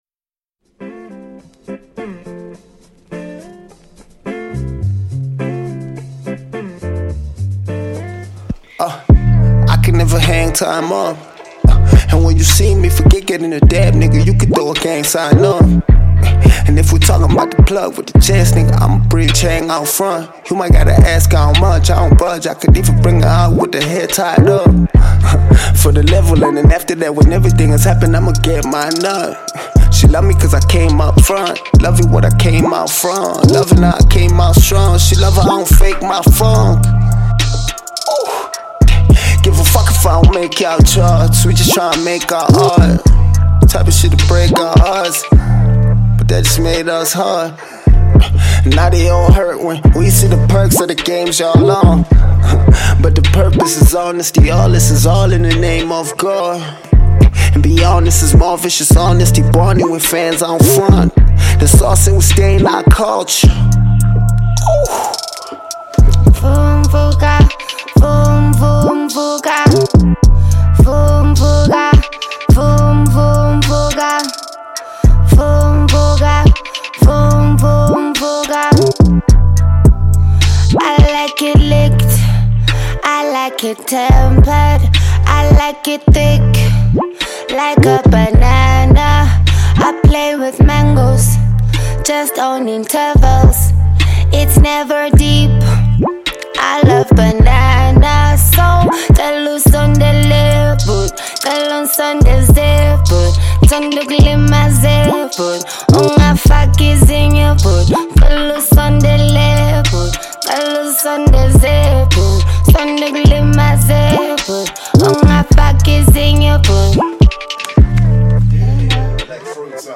SA hip-hop rapper
south African female vocalists